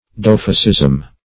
Meaning of doughfaceism. doughfaceism synonyms, pronunciation, spelling and more from Free Dictionary.
Search Result for " doughfaceism" : The Collaborative International Dictionary of English v.0.48: Doughfaceism \Dough"face`ism\, n. The character of a doughface; truckling pliability.